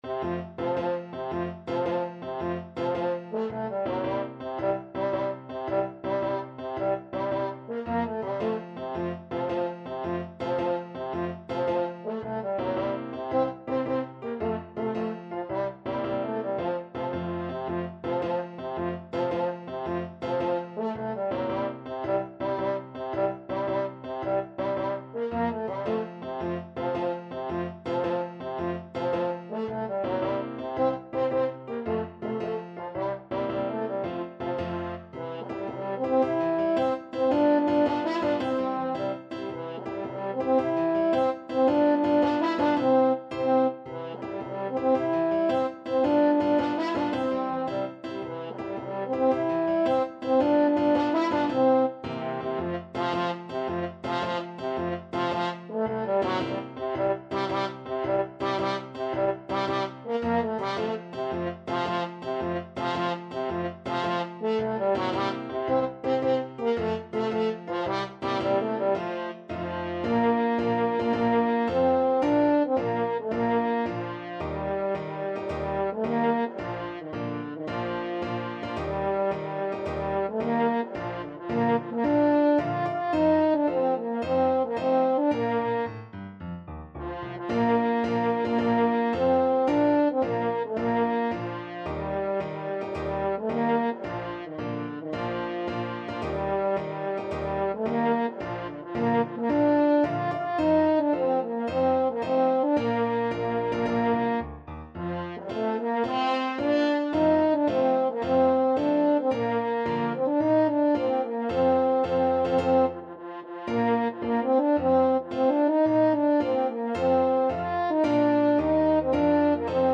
Free Sheet music for French Horn
F major (Sounding Pitch) C major (French Horn in F) (View more F major Music for French Horn )
6/8 (View more 6/8 Music)
Classical (View more Classical French Horn Music)